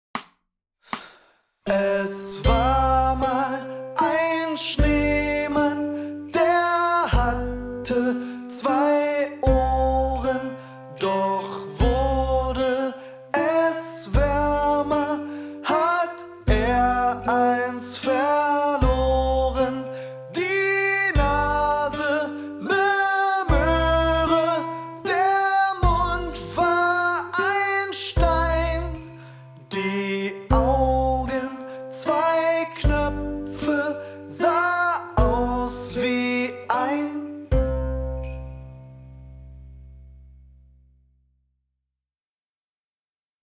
langsam